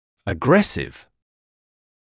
[ə’gresɪv][э’грэсив]агрессивный